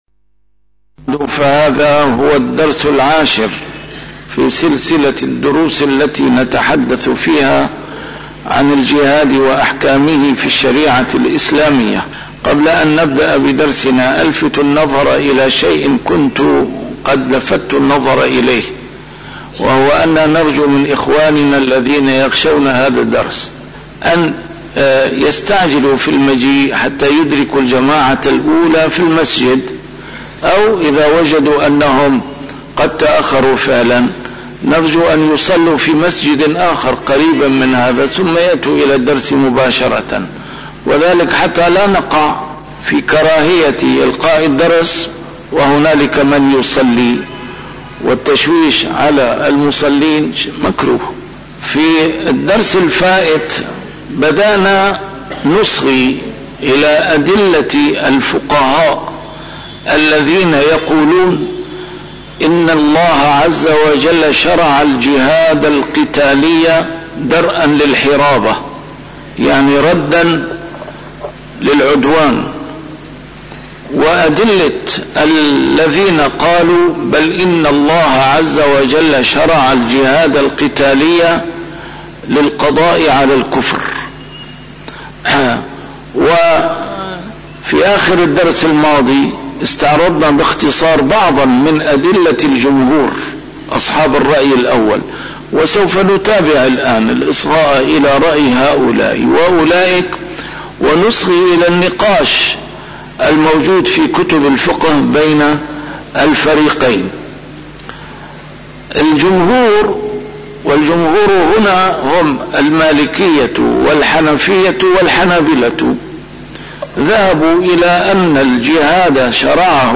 الدرس العاشر: الجهاد الحصن الأول لحماية المجتمع الإسلامي ودار الإسلام